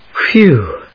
/(h)wúː(米国英語), fjúː(英国英語)/